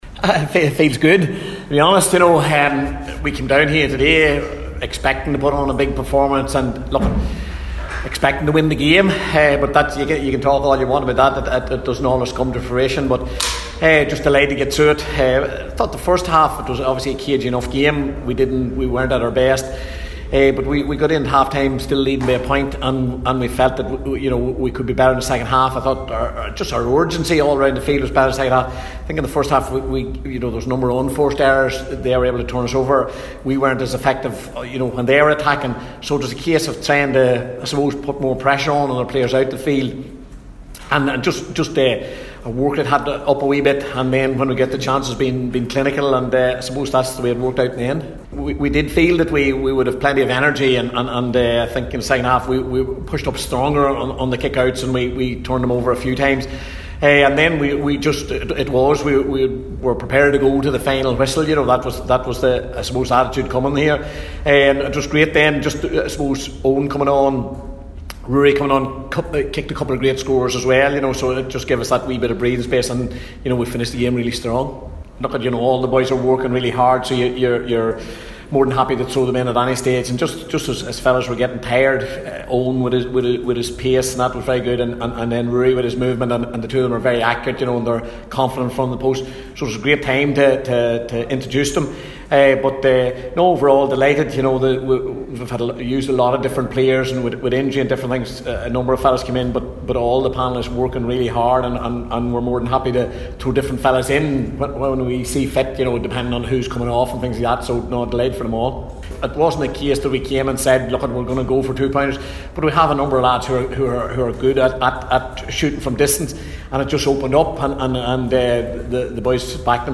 O’Rourke spoke to the assembled media after the game and said his team came to GAA Headquarters expecting to win the game…